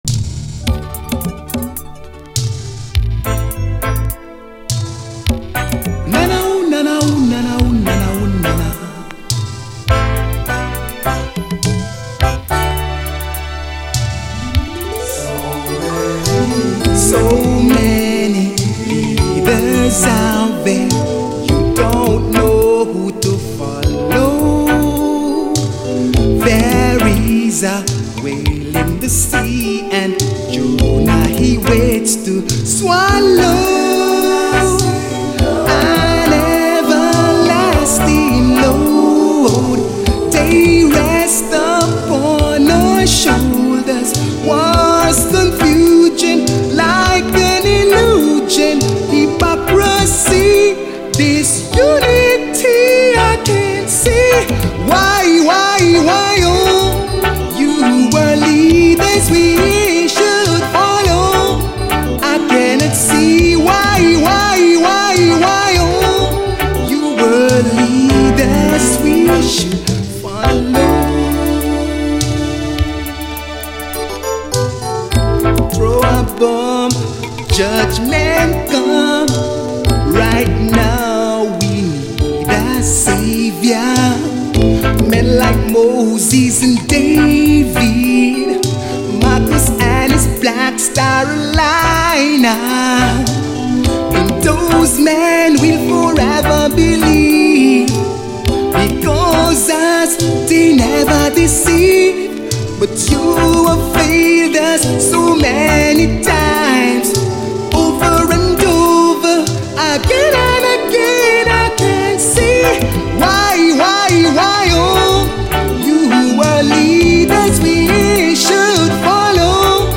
REGGAE
エレピがアーバンに悩ましく光る都会的メロウ・レゲエ
89年US産マイナー・ルーツ・レゲエ！